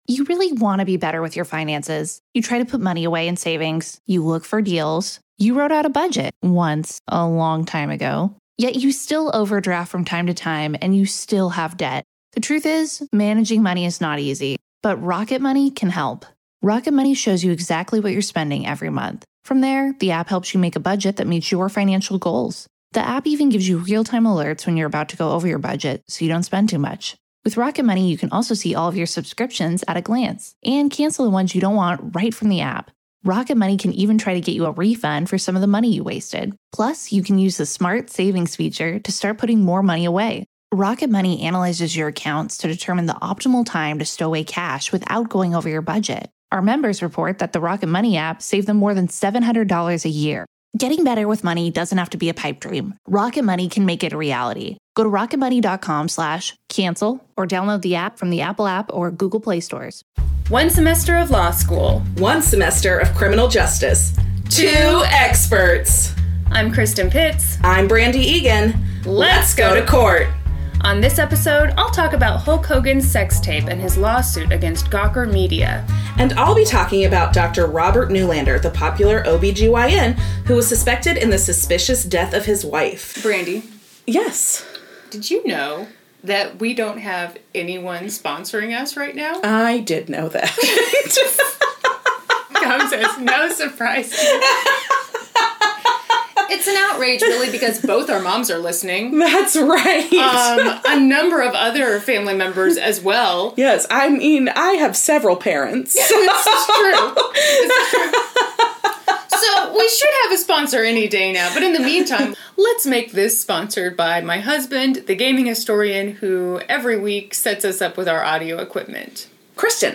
WARNING: The audio in this episode is rough.
We were young(ish), dumb, and thought we’d save a little money by sharing one microphone.